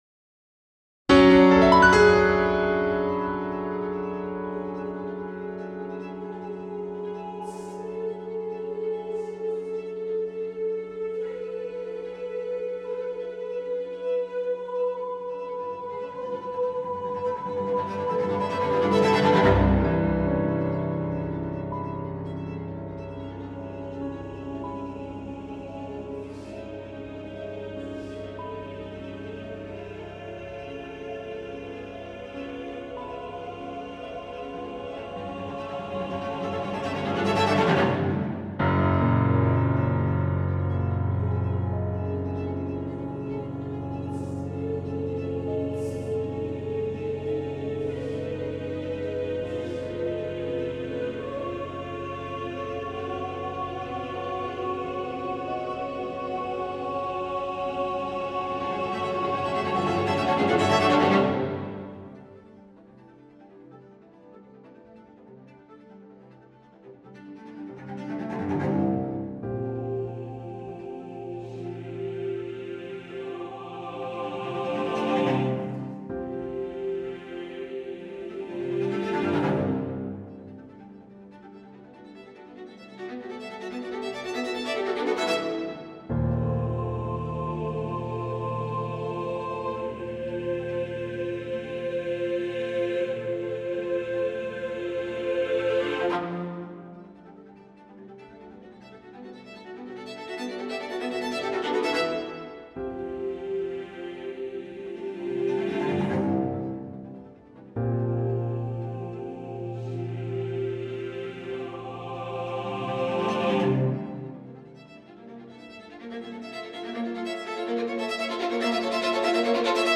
a choral and chamber music composition in nine movements